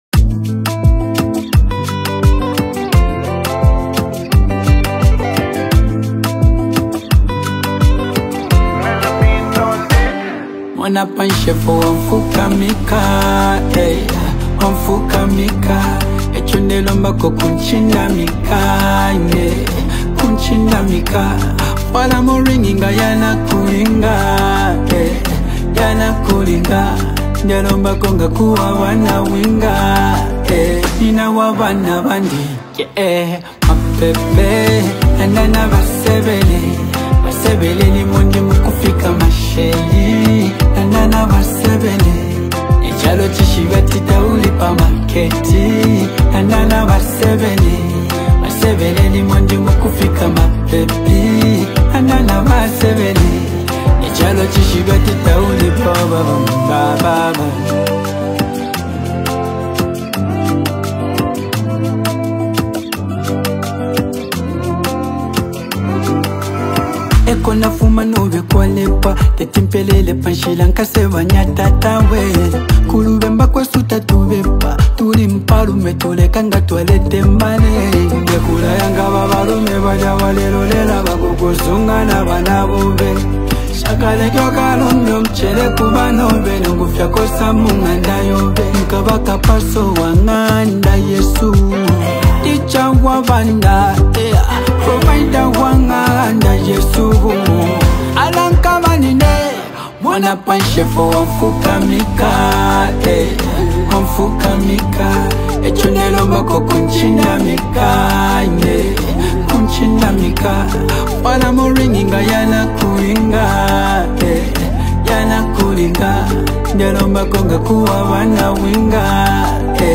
energetic rap